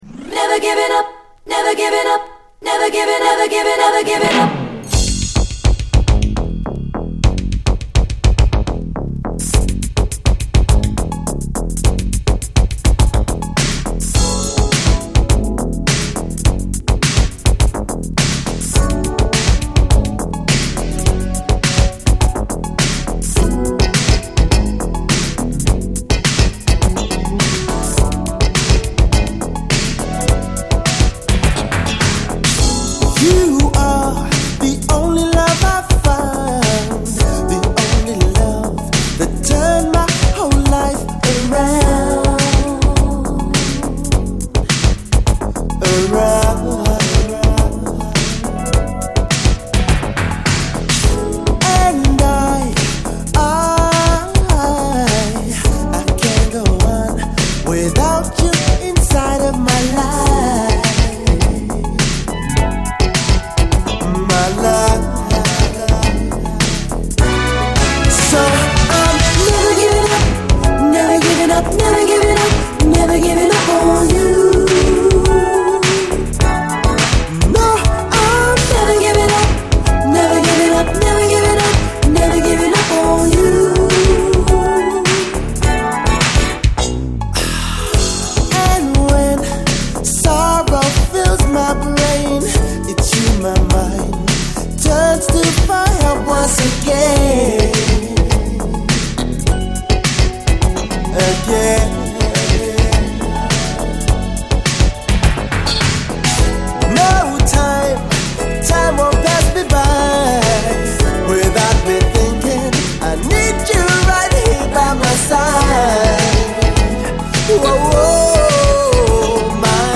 SOUL, 70's～ SOUL
87年UKストリート・ソウル！アーバンでありつつもエレクトロ由来のビートと黒いフィーリングが渋い！